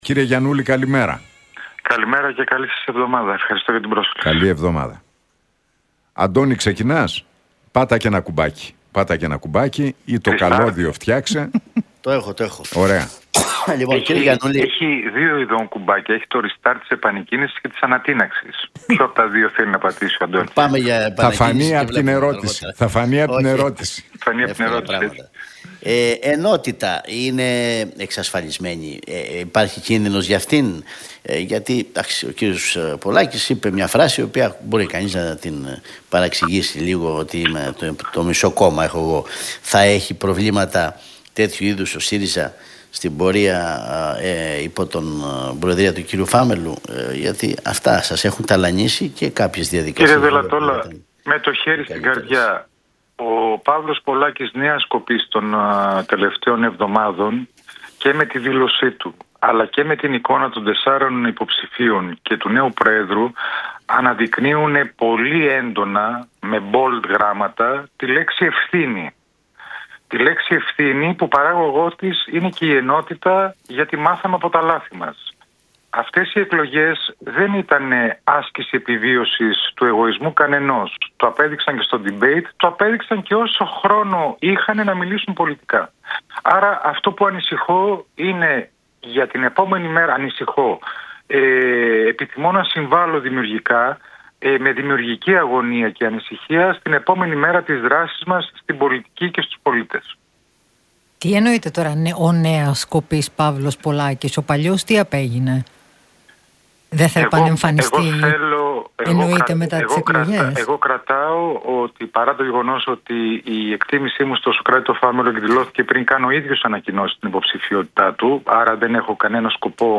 Για τις εκλογές στον ΣΥΡΙΖΑ, τη νίκη του Σωκράτη Φάμελλου και την επόμενη ημέρα μίλησε ο Χρήστος Γιαννούλης στον Realfm 97,8 και τους Νίκο Χατζηνικολάου,